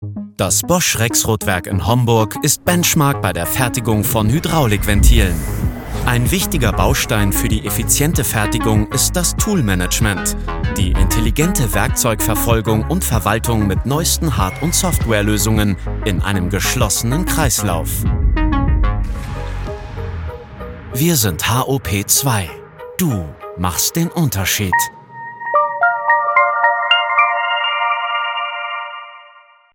dunkel, sonor, souverän, markant, sehr variabel
Mittel minus (25-45)
Imagefilm - sachlich & direkt
Commercial (Werbung), Presentation, Narrative